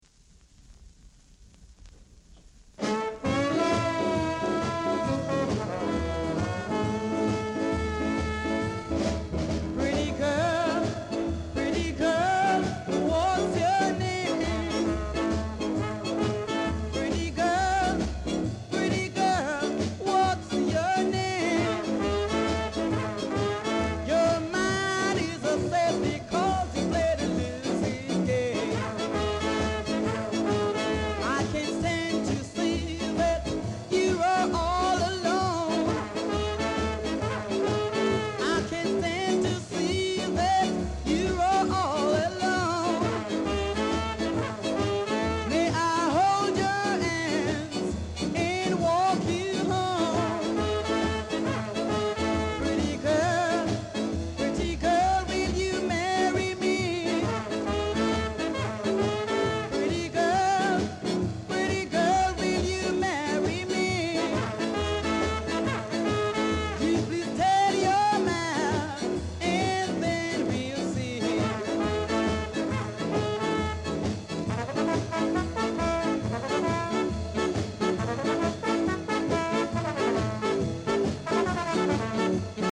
Ska Male Vocal
Great early ska vocal w-sider!